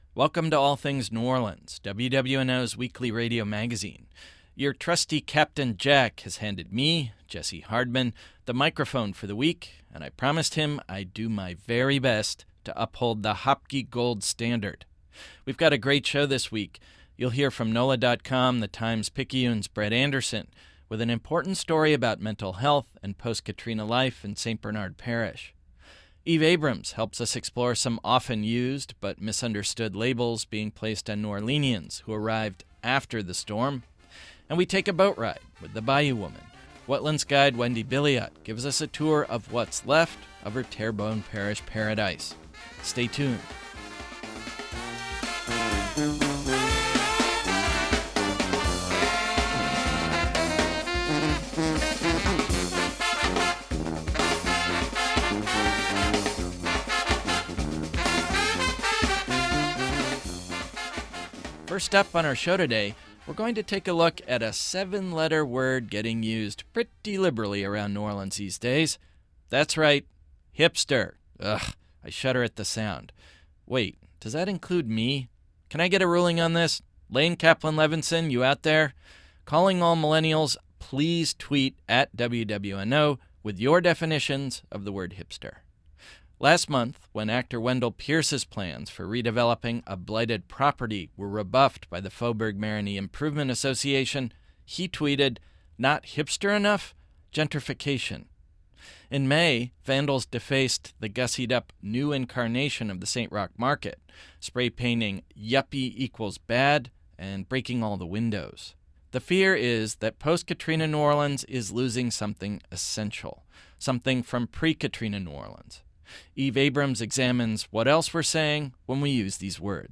This week on All Things New Orleans, WWNOs weekly local magazine: